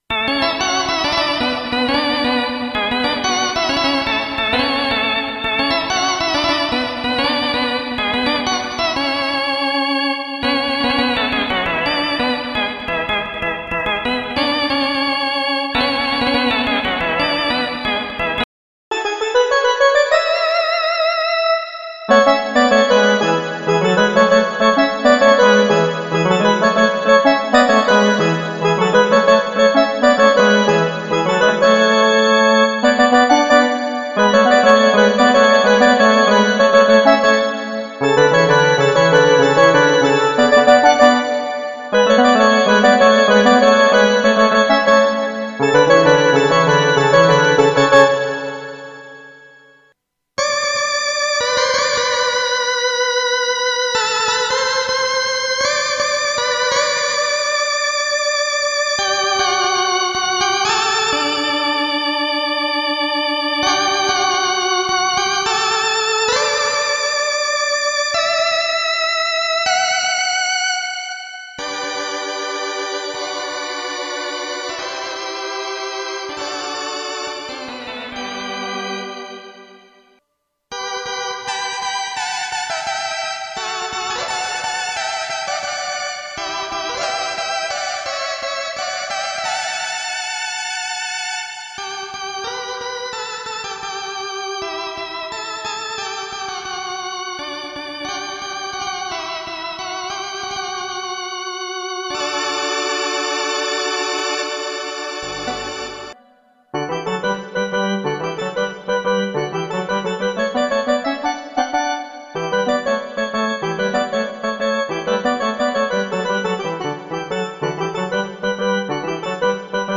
¡Lleva el sonido auténtico de la onda grupera a tu Korg Kronos!
• Hammond B200: Los órganos vibrantes y con ese “click” característico de la cumbia pegassera.
• Technics C600: Sonidos de acompañamiento y leads clásicos de la marca.
• Kurzweil K1000: Los pianos y strings esenciales para las baladas y ritmos del género.
• Roland D50 & Yamaha DX7: Los sintetizadores que aportaron el brillo y la textura digital única de los años 80 y 90.
• Multisamples Reales: Cada sonido fue sampleado nota por nota para mantener la dinámica y el realismo en todo el teclado.